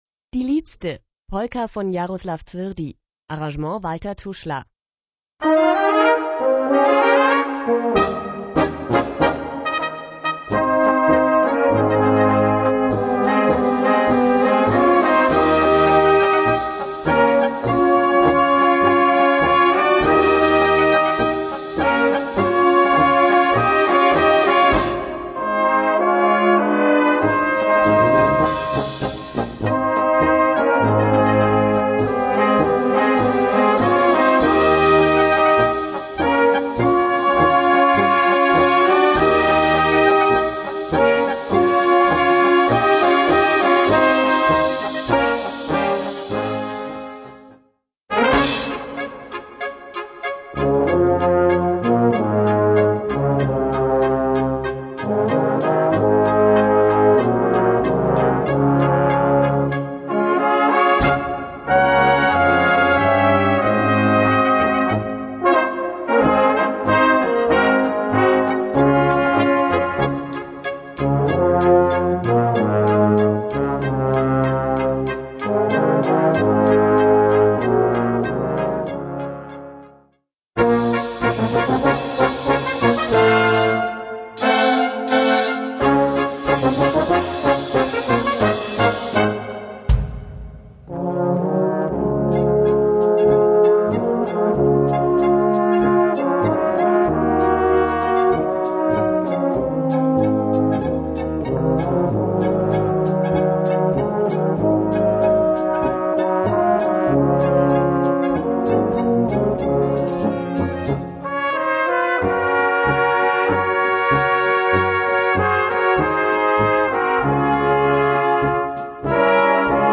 Gattung: Böhmische Polka
Besetzung: Blasorchester